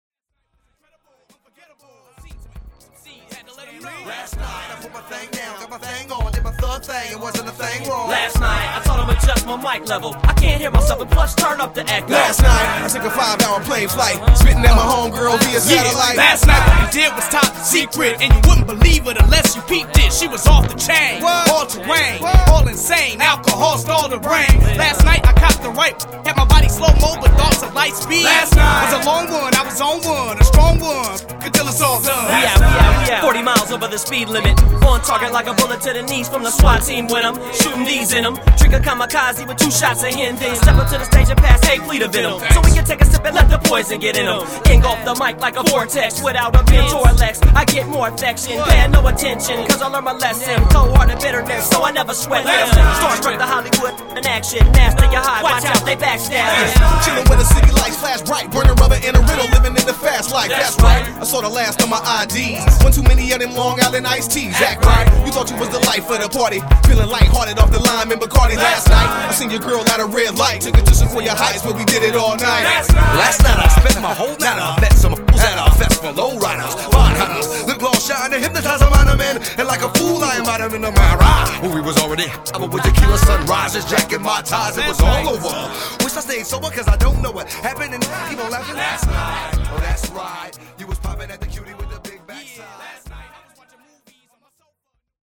lends scratching talents throughout the record